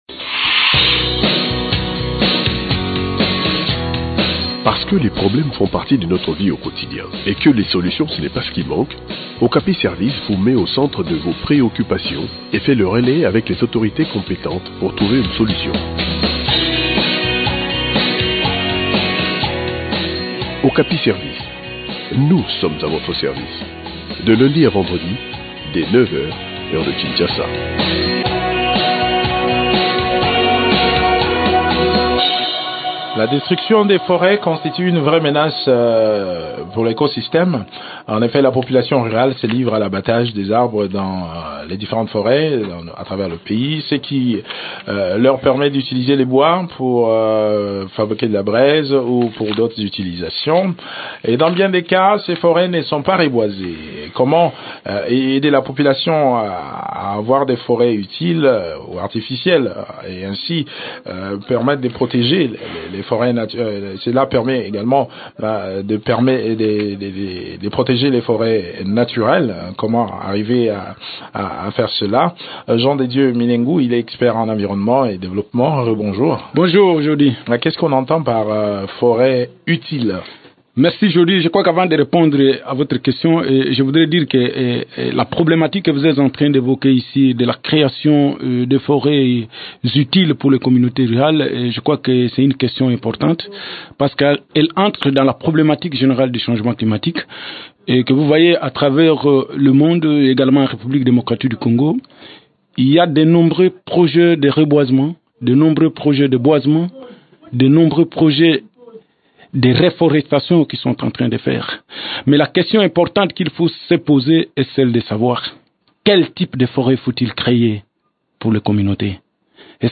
expert en environnement et développement